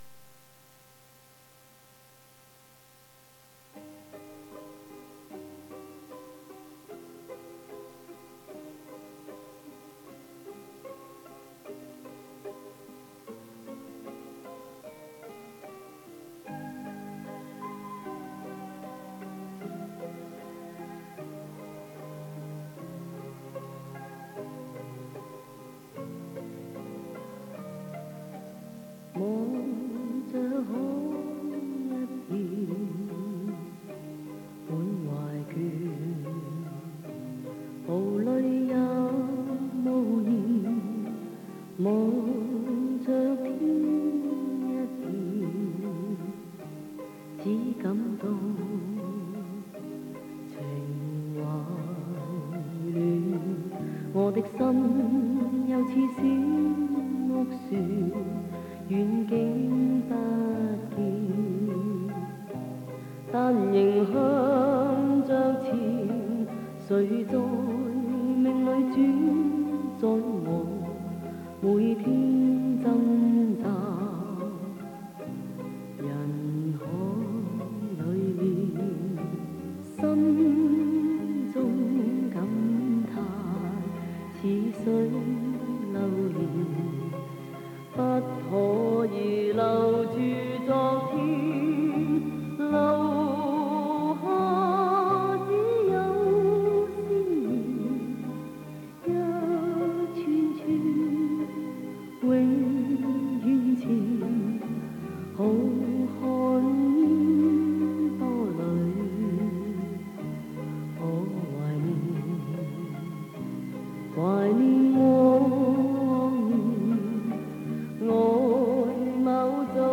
磁带数字化：2022-07-11
女歌手也可以表现得很放肆，可以中性化得非常帅，可以面无表情。